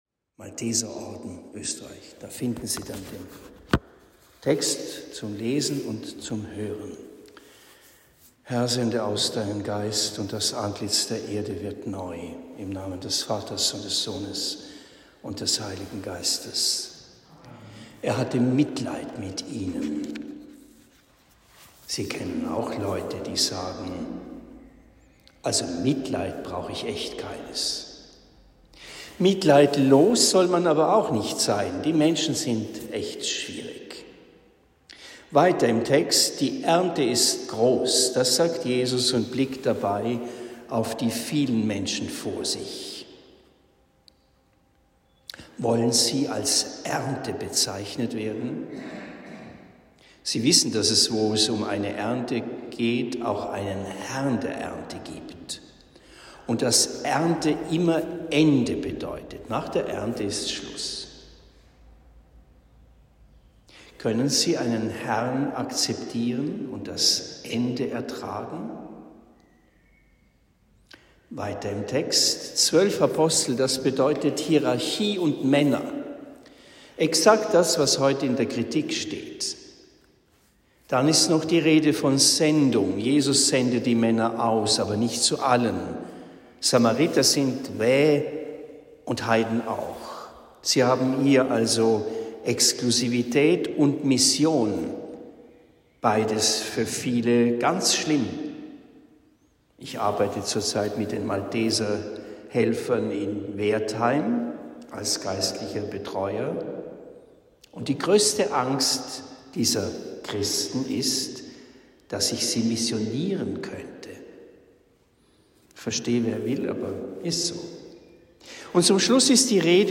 Predigt in Rothenfels am 17. Juni 2023